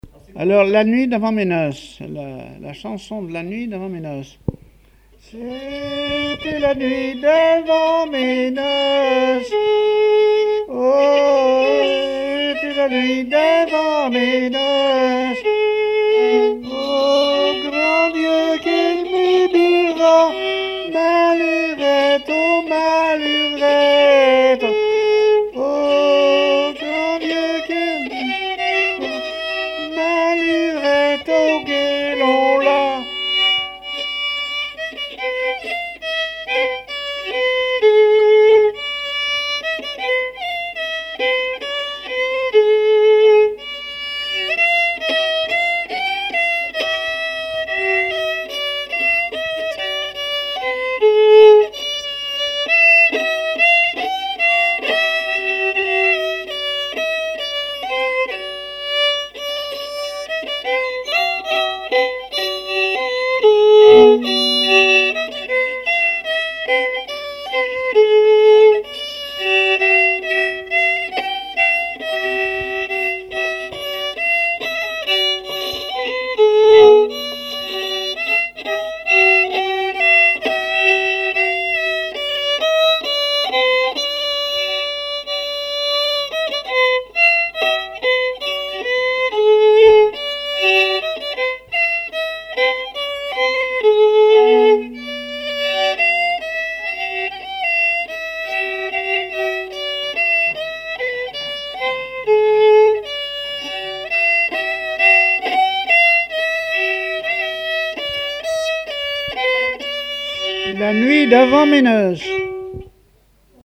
répertoire musical au violon
Pièce musicale inédite